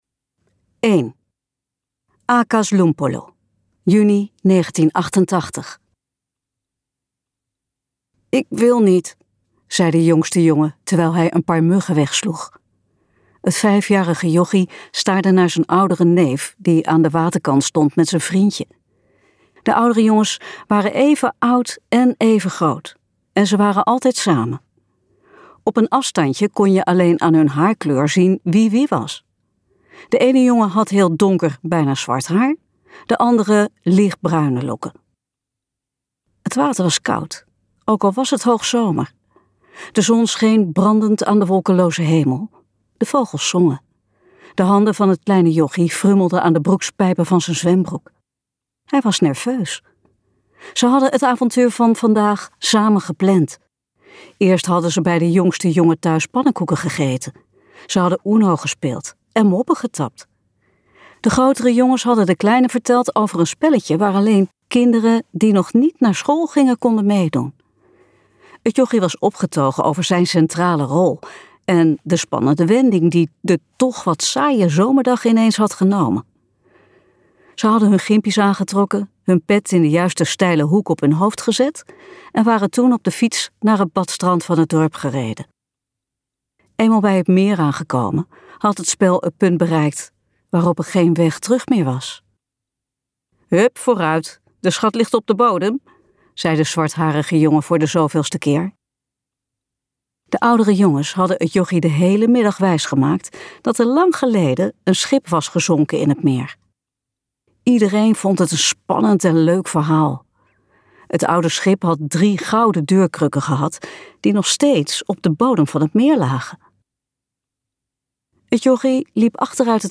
Ambo|Anthos uitgevers - Jakob luisterboek